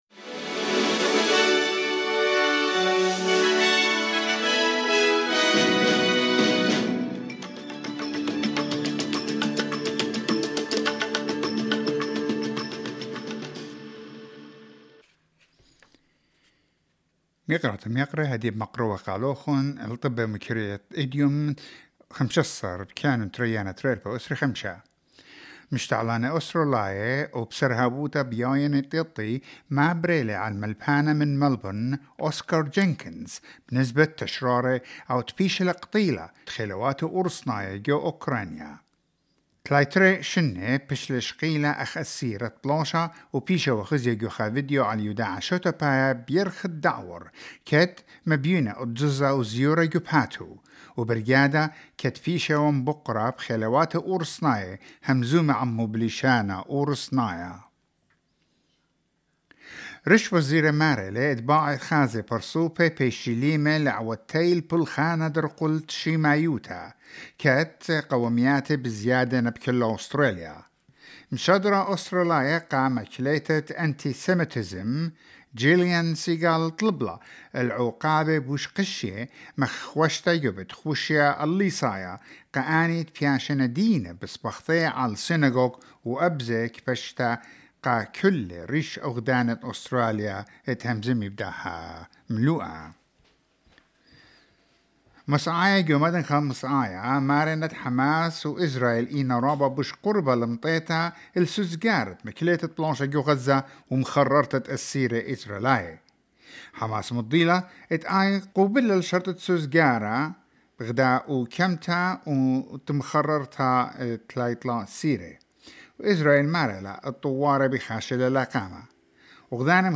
Newsflash: 15 January 2025